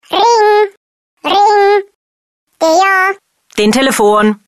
Catégorie Drôle